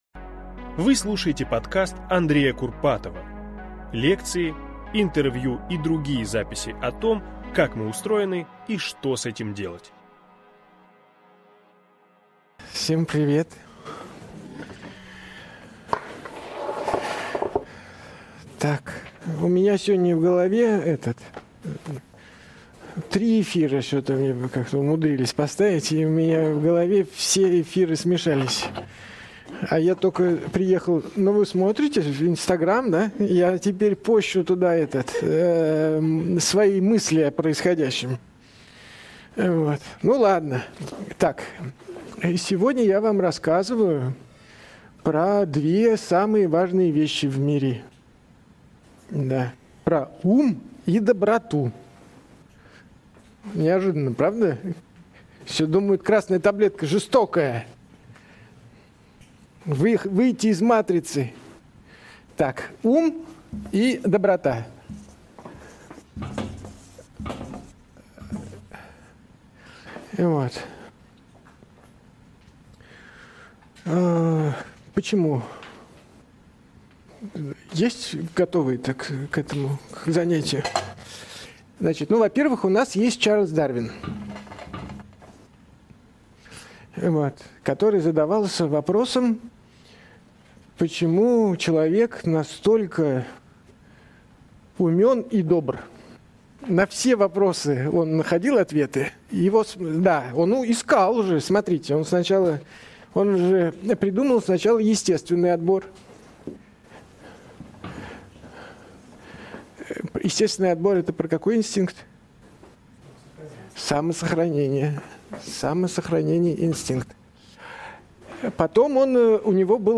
Аудиокнига Майндсет: можно ли изменить мышление и добиться успеха?